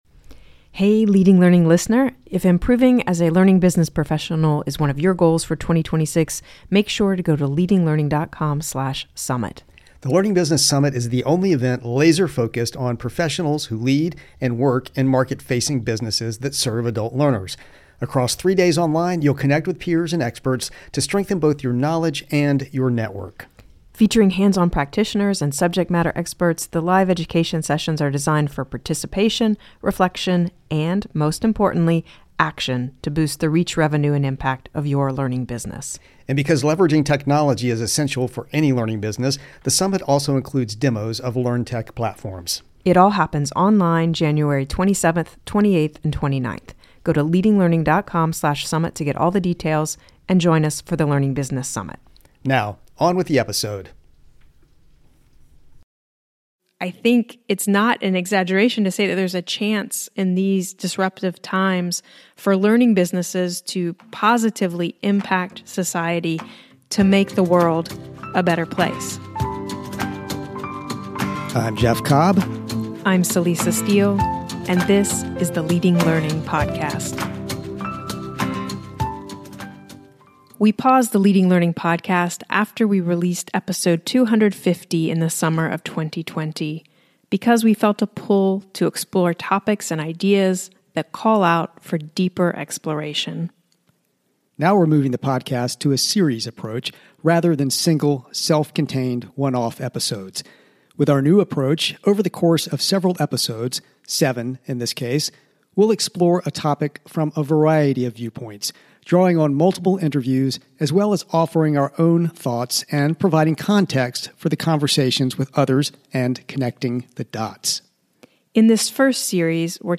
With our new approach, over the course of several episodes—seven in this case—we’ll explore a topic from a variety of viewpoints, drawing on multiple interviews as well as offering our own thoughts and providing context for the conversations with others.